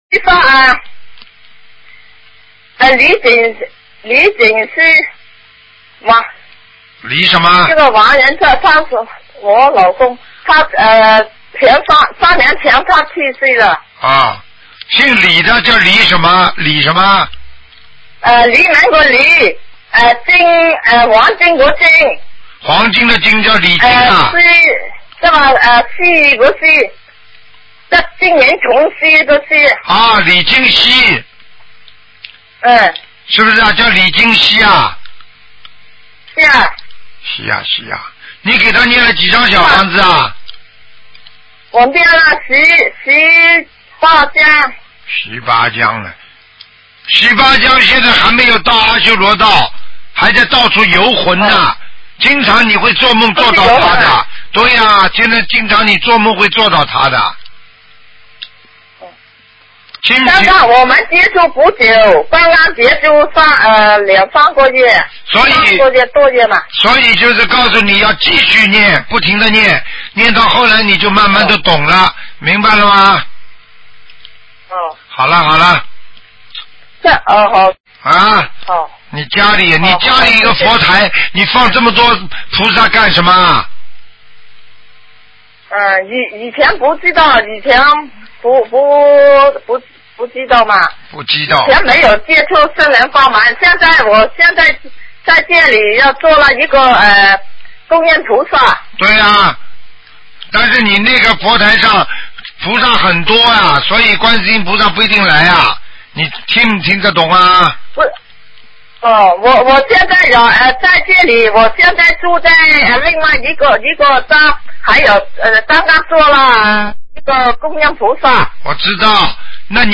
目录：2014年_剪辑电台节目录音集锦